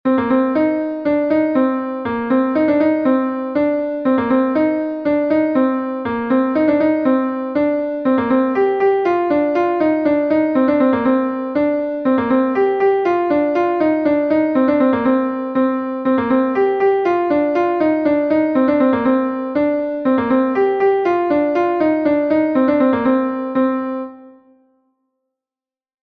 Gavotenn Leuelan II is a Gavotte from Brittany